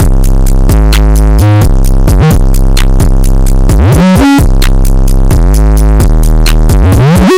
非常好的鼓
描述：非常好的鼓，非常好的鼓，非常好的鼓，非常好的鼓，非常好的鼓，非常好的鼓。
Tag: 130 bpm Trap Loops Drum Loops 1.24 MB wav Key : Unknown